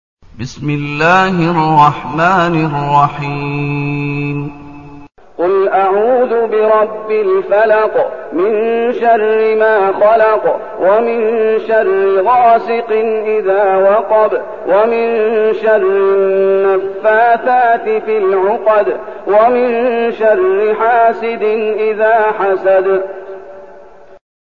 المكان: المسجد النبوي الشيخ: فضيلة الشيخ محمد أيوب فضيلة الشيخ محمد أيوب الفلق